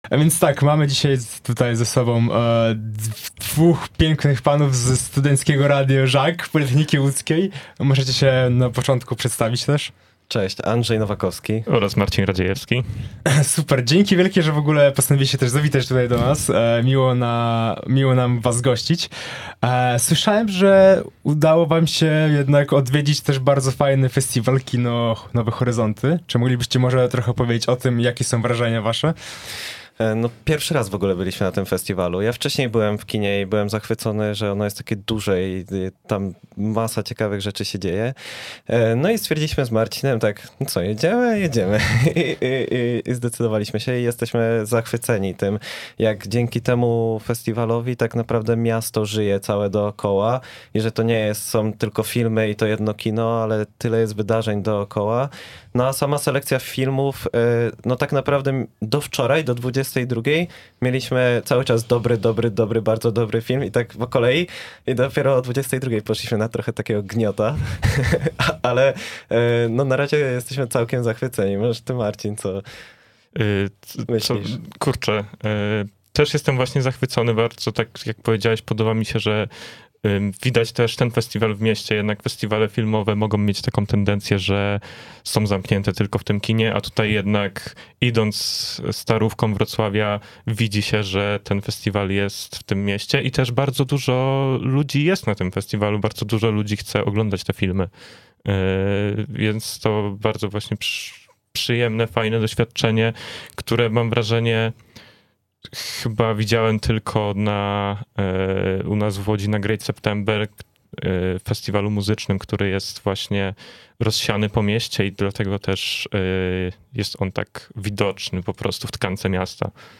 W coniedzielnej audycji Szto tam? również byliśmy połączeni filmową ciekawością z gośćmi z łodzkiego Radia Żak, którzy odwiedzili ostatnie dni festiwalu i podzielili się swoimi wrażeniami: